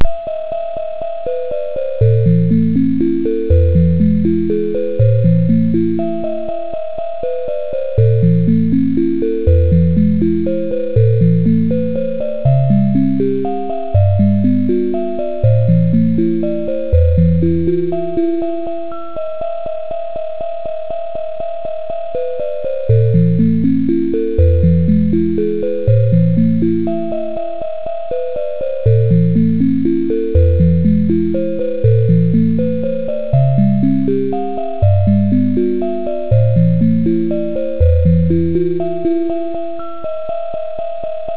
9月7日 10月号関係で作ったPSoC電子オルゴールの
テスト音（約124Kバイト）と、サンプル曲（1293Kバイト）